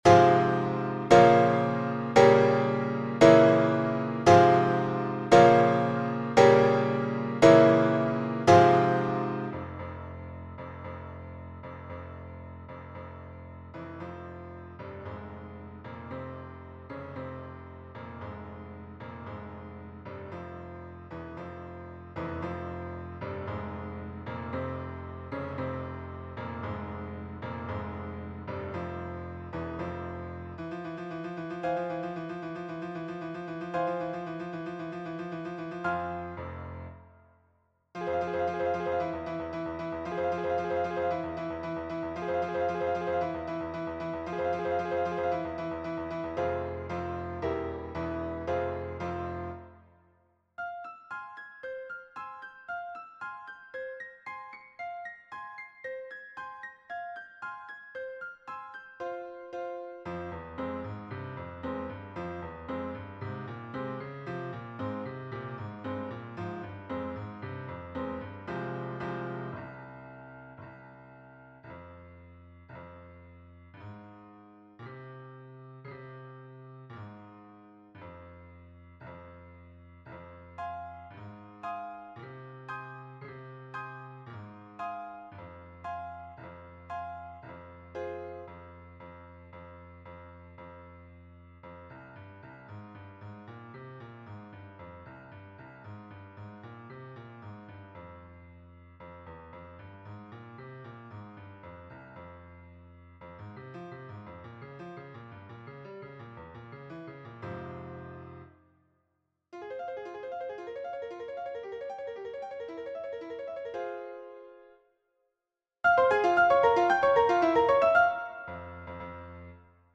Rapsodie in F mineur